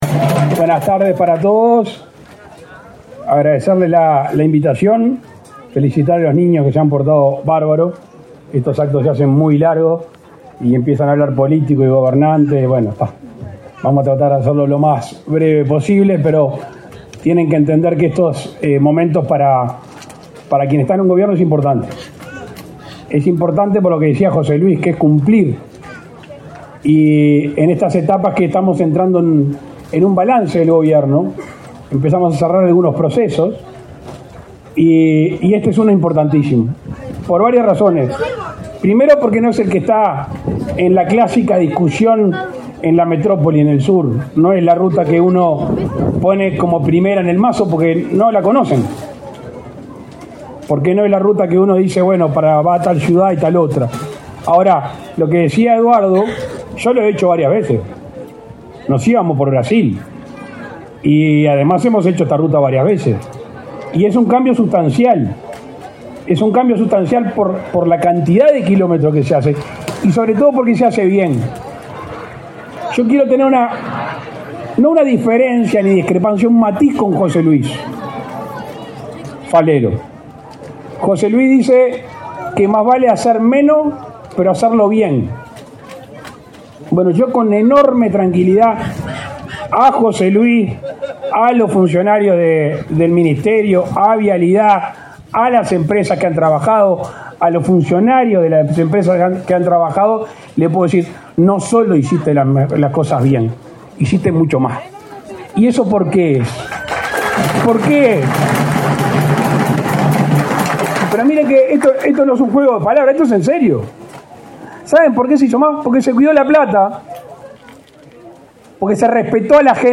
Palabras del presidente de la República, Luis Lacalle Pou
Palabras del presidente de la República, Luis Lacalle Pou 09/10/2024 Compartir Facebook X Copiar enlace WhatsApp LinkedIn En el marco de la ceremonia de inauguración de obras en la ruta n.º 30, en el tramo comprendido entre Artigas y Tranqueras, este 9 de octubre, se expresó el presidente de la República, Luis Lacalle Pou.